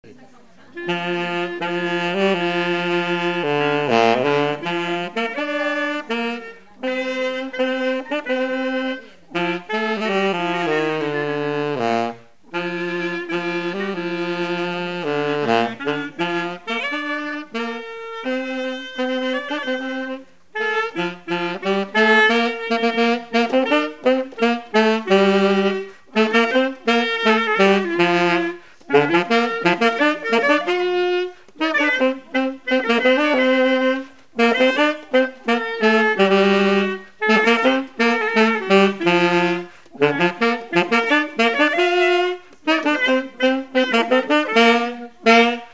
instrumental
Chansons traditionnelles et populaires